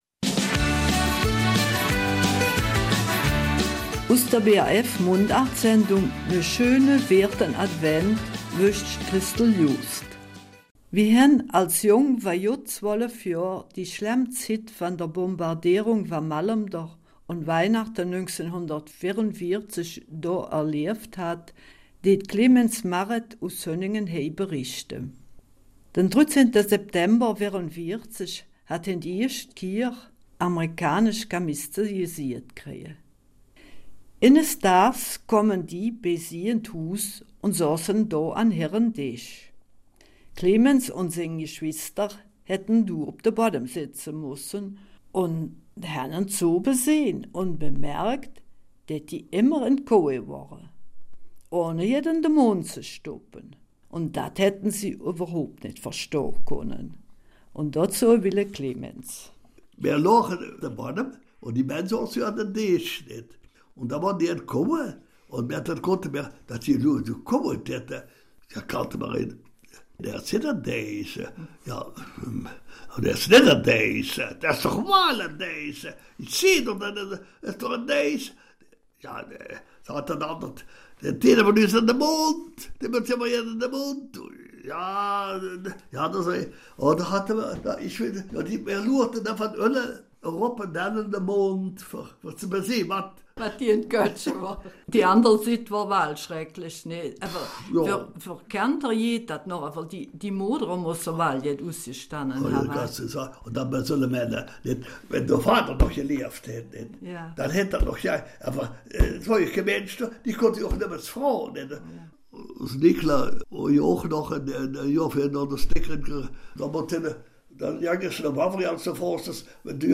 Eifeler Mundart: Weihnachten 1944 in Malmedy
Wie er Monate später sein Zuhause in Hünningen vorgefunden hat und wie das Leben danach weiterging, berichtet er in der Mundartsendung an diesem Sonntag.